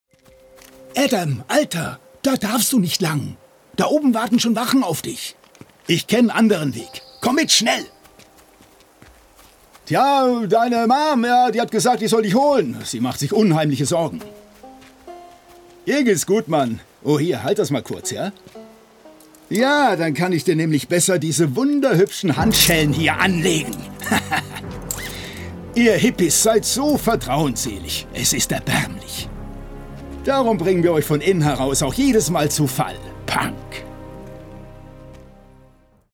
Sprachproben
Natürliche Stimme